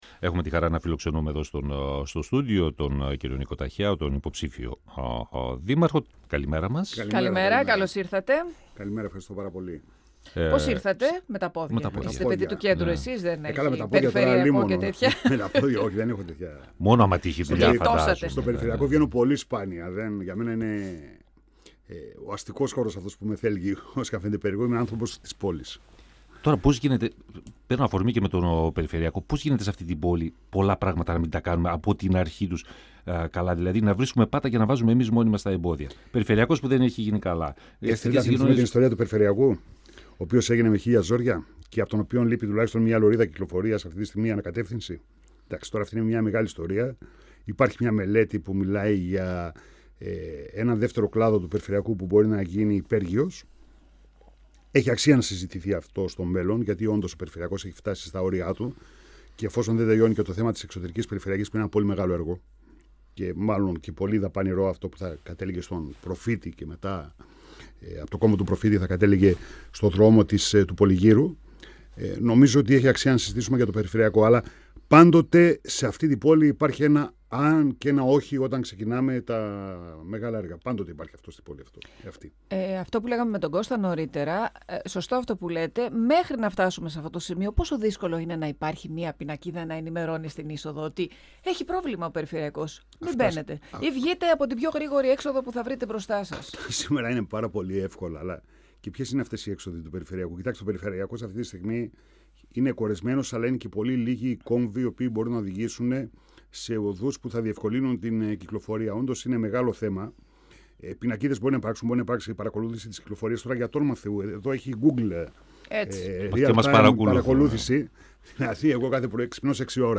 Τις προθέσεις του ενόψει των δημοτικών εκλογών καθώς και τα σχέδιά του, σε περίπτωση που αναλάβει τον δήμο Θεσσαλονίκης, ανέπτυξε ο υποψήφιος δήμαρχος Νίκος Ταχιάος μιλώντας στα μικρόφωνα του 102FM του Ραδιοφωνικού Σταθμού Μακεδονίας της ΕΡΤ3. Ο κ. Ταχιάος ανέφερε ότι υπάρχει θέμα εγκατάλειψης της νέας παραλίας και είναι απαραίτητη η φύλαξη των χώρων της, τάχθηκε υπέρ της ενοικίασης των περιπτέρων ώστε να υπάρχουν έσοδα, διευκρινίζοντας ότι πρέπει να υπάρχει έλεγχος τόσο για τα τραπεζοκαθίσματα όσο και για το ύψος του κόστους διάθεσης προϊόντων στους καταναλωτές.
Ο κ. Ταχιάος αναφέρθηκε στα νέα δεδομένα που προκύπτουν από το σύστημα της απλής αναλογικής στις δημοτικές εκλογές και απέκλεισε το ενδεχόμενο συνεργασίας με τον Παναγιώτη Ψωμιάδη. 102FM Συνεντεύξεις ΕΡΤ3